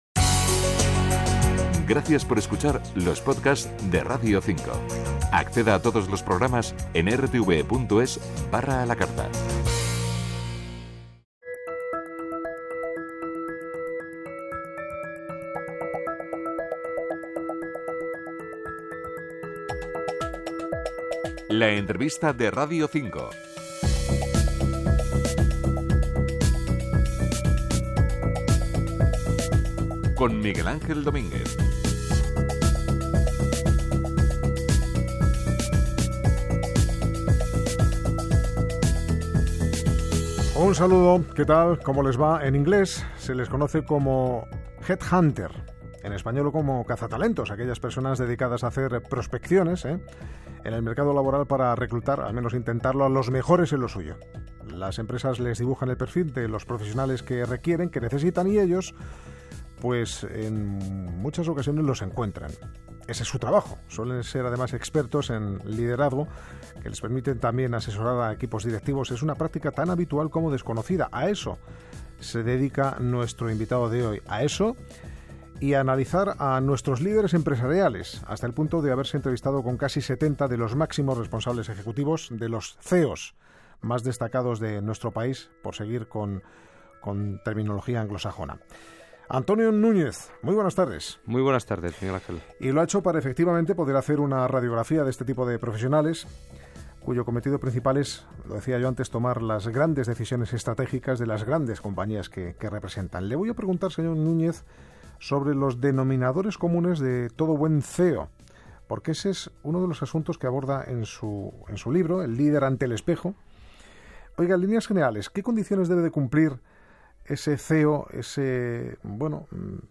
Entrevista de Radio 5